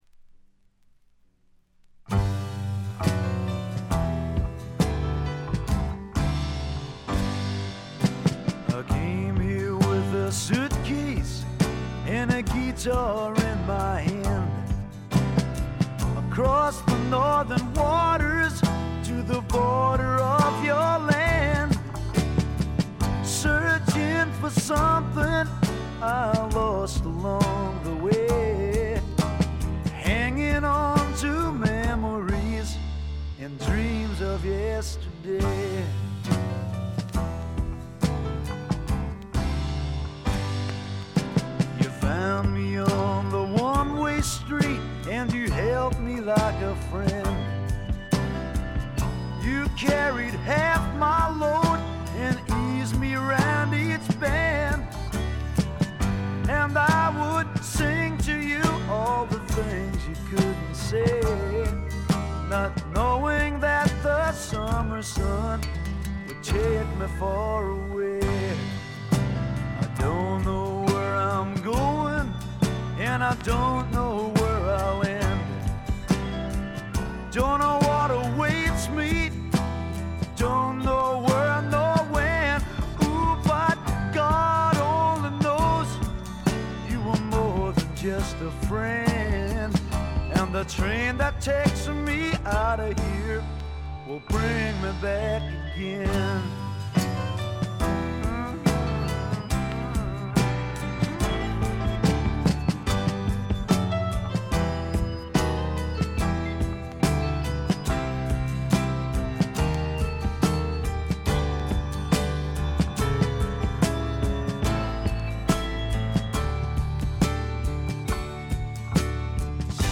部分試聴ですが、静音部での軽微なバックグラウンドノイズ程度。
なにはともあれ哀愁の英国スワンプ／英国フォークロック基本中の基本です。
試聴曲は現品からの取り込み音源です。